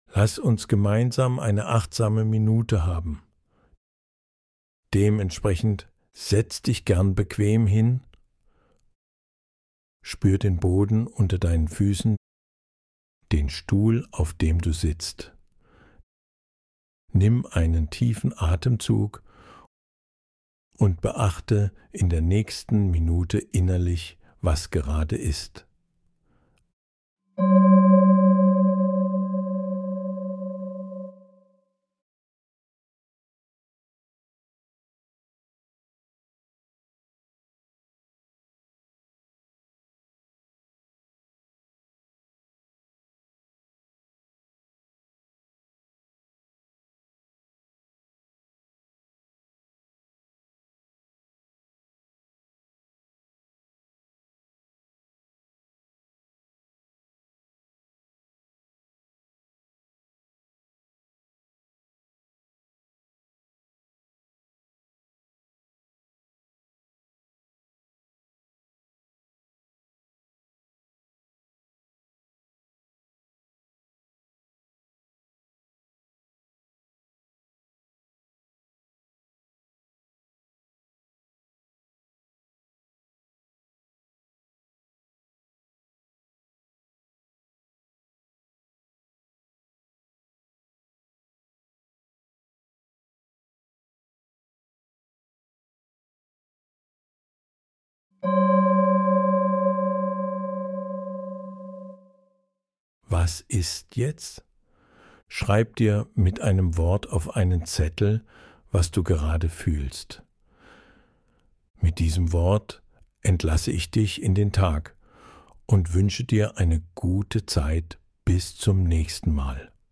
Geführte Meditationen
Dieselbe kurze Achtsamkeitsübung, gesprochen von einer männlichen Stimme.
~ 1 Minute Männliche Stimme
achtsame-minute-male.mp3